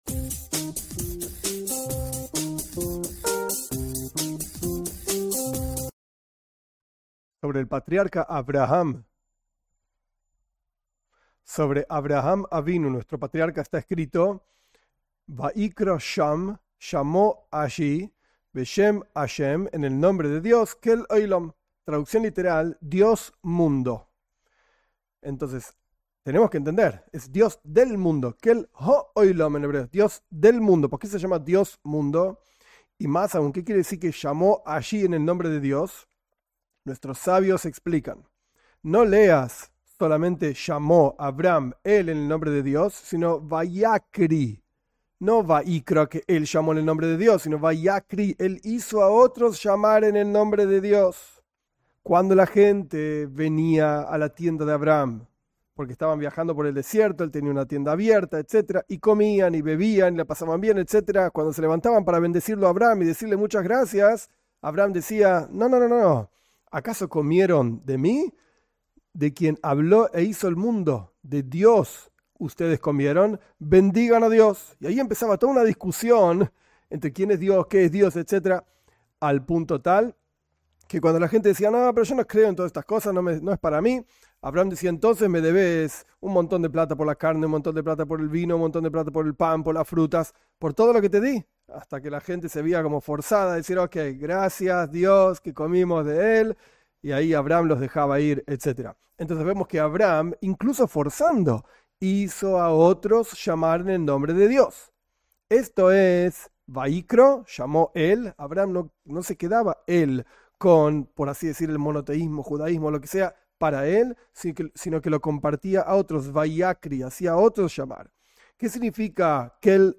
En esta pequeña clase aprendemos sobre el trabajo de Avraham, nuestro patriarca, difundiendo las ideas del monoteísmo a todos, enseñando sobre que lo único que existe es Di-s. Basado en Bati leGani 5711.